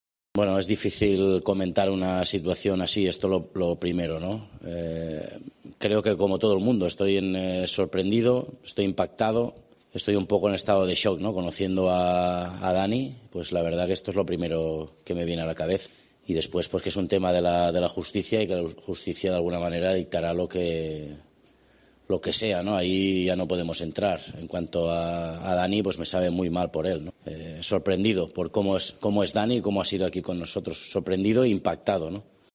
Barcelona | Rueda de Prensa